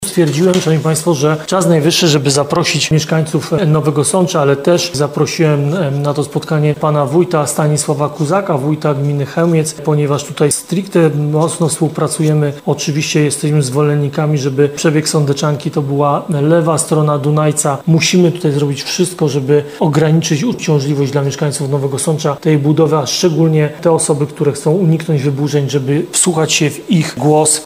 – mówi prezydent Ludomir Handzel podczas czata online z mieszkańcami.